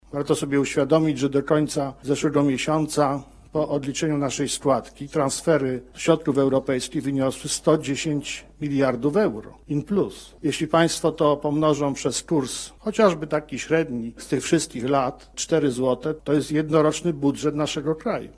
W Centrum Nauki Kopernik zorganizowano debaty z udziałem samorządowców, ekspertów oraz młodych osób działających na rzecz klimatu i edukacji.
– To udało się dzięki funduszom unijnym – podkreślił marszałek województwa Adam Struzik.